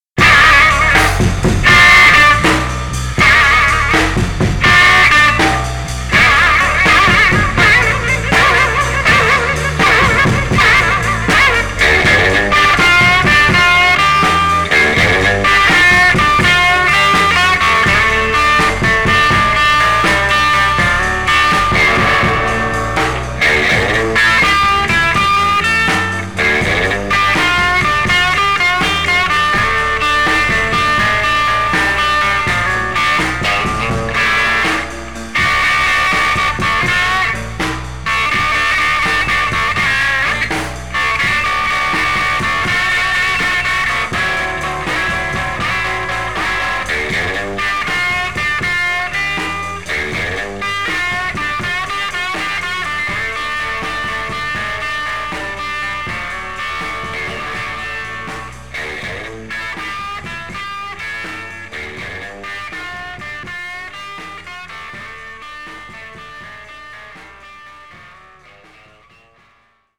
the mean and dirty